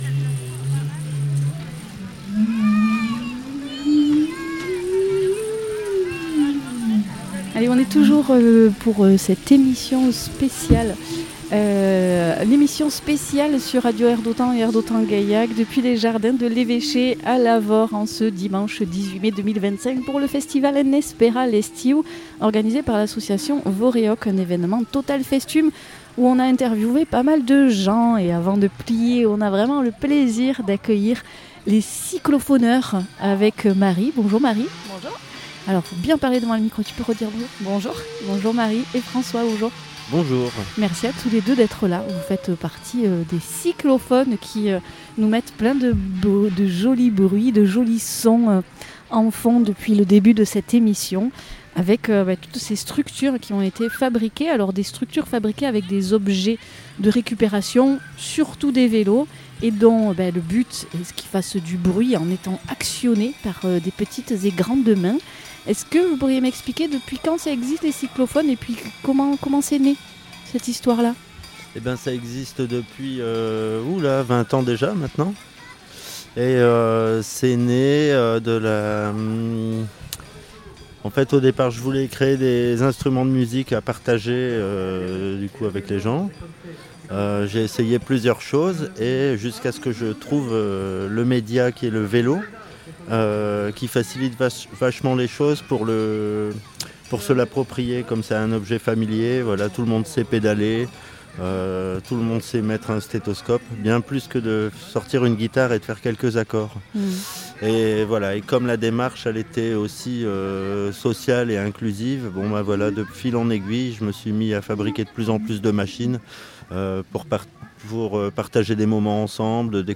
Interview
au festival "Total Festum, En Esperar L’Estiu "